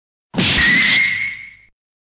Download Anime Voicemod sound effect for free.
Anime Voicemod